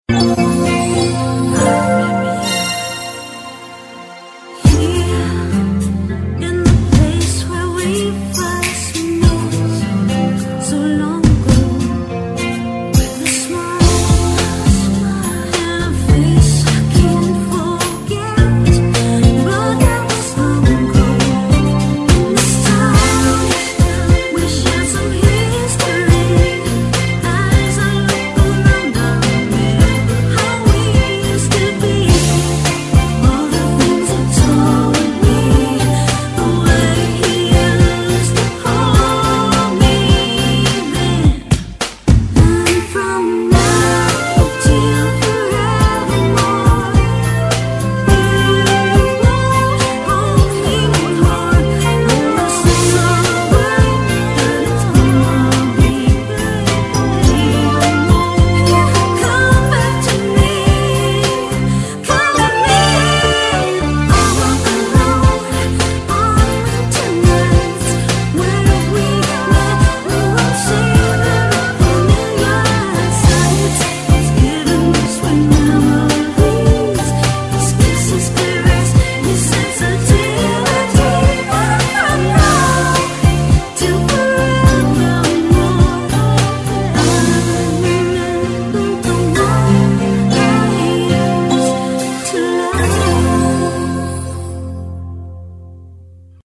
BPM40-105
Audio QualityPerfect (High Quality)